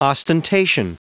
Prononciation du mot ostentation en anglais (fichier audio)
Prononciation du mot : ostentation